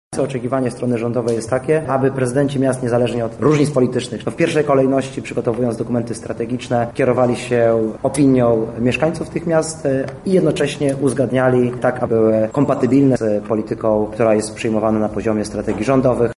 O tym jak kwestia dofinansowania wygląda ze strony rządowej mówi wiceminister Inwestycji i Rozwoju Artur Soboń: